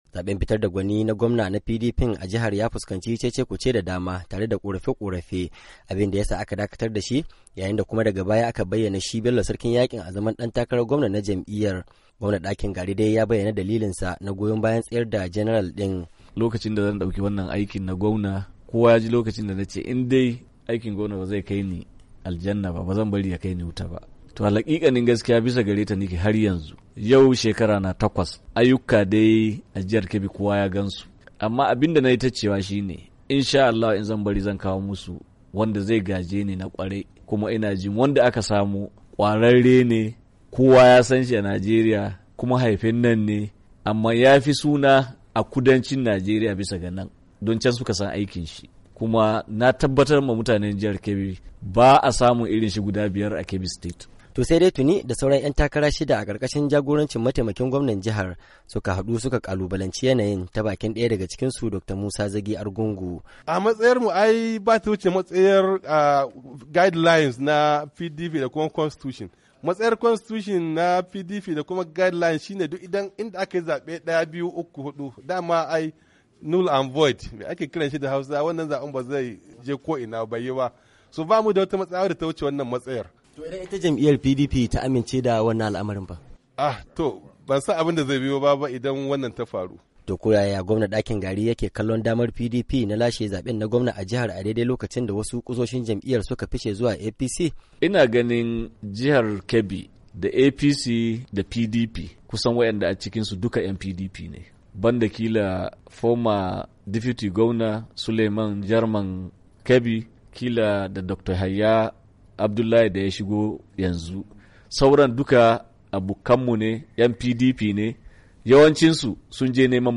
A cikin hirarsu da Sashen Hausa.